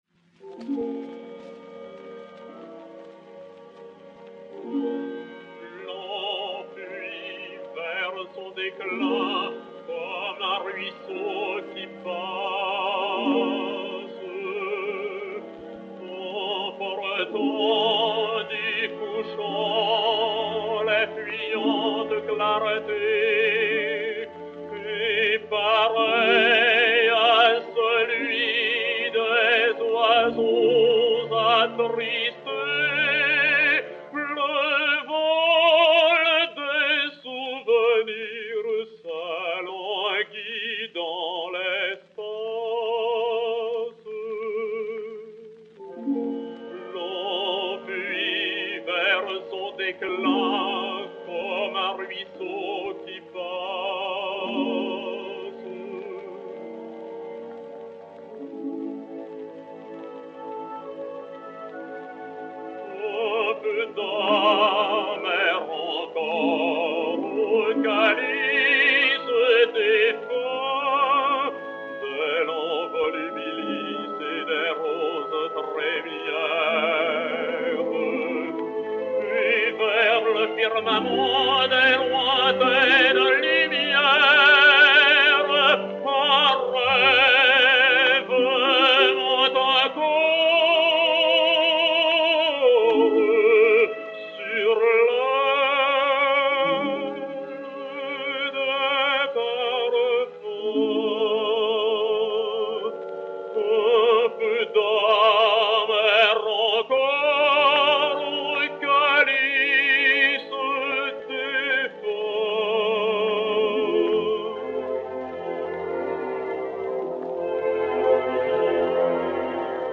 baryton, avec orchestre